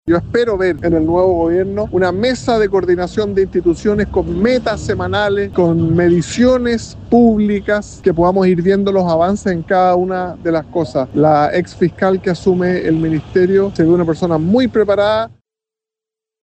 En la misma línea, el diputado UDI e integrante de la Comisión de Seguridad, Jorge Alessandri, recalcó el énfasis que tendrá el tema y la necesidad de avanzar con medidas concretas.